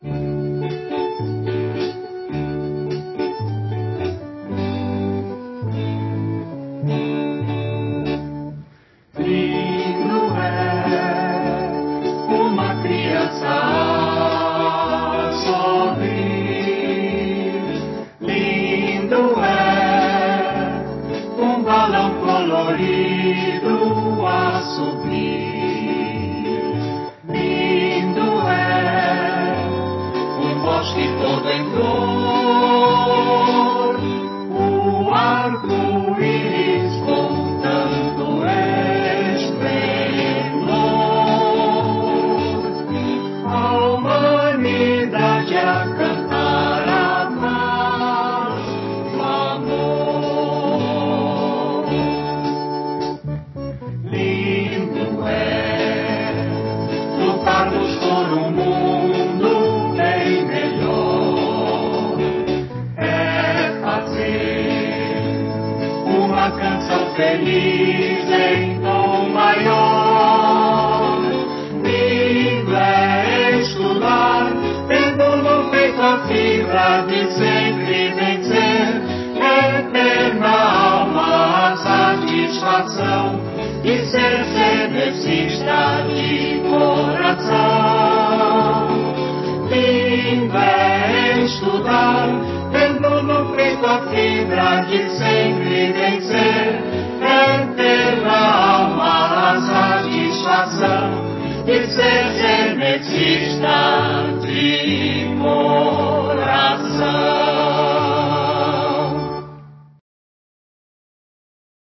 hino